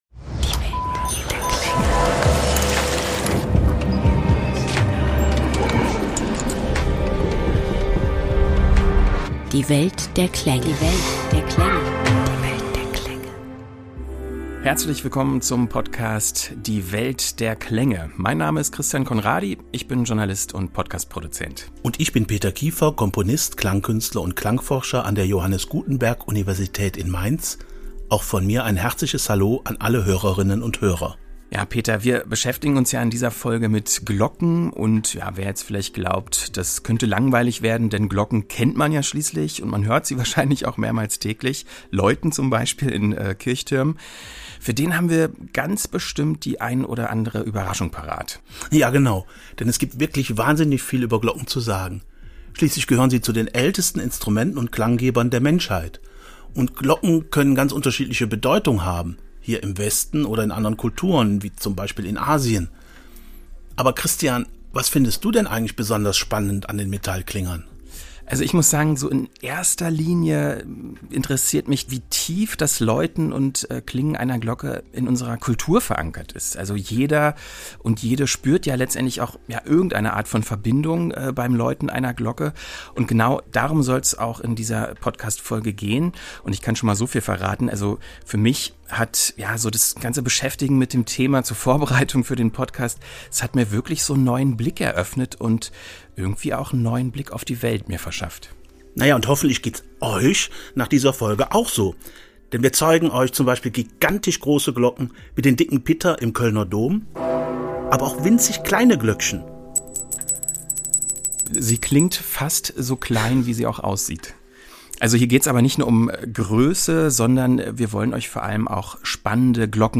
Wir reisen nach Japan und hören die beeindruckenden Glockenschläge des Neujahrsrituals Joya no Kane im Chion-In-Tempel in Kyoto und lüften das Geheimnis der ältesten datierbaren Glocke Deutschlands, der über 1000 Jahre alten Lullusglocke in Bad Hersfeld. Außerdem lernt ihr, welche Rolle Glocken in der Wüste oder auf hoher See spielten – und warum sie sogar in YouTube-Benachrichtigungen weiterleben!